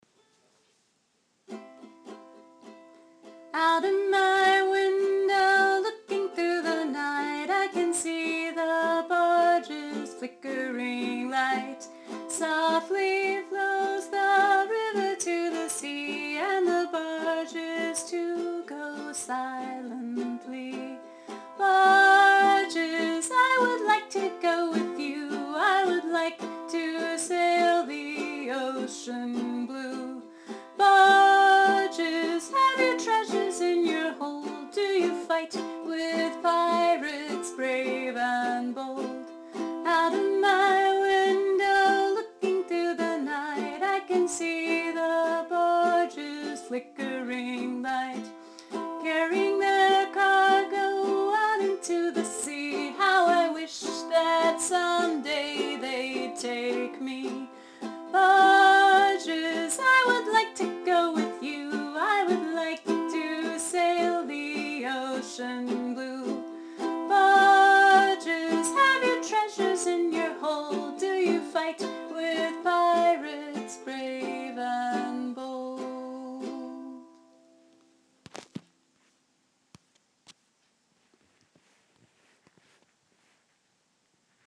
Posted in Rhymes and songs, Storytime themes, Ukulele by
On the ukulele, you can play it by cycling through C, F, and G7 all the way through the song: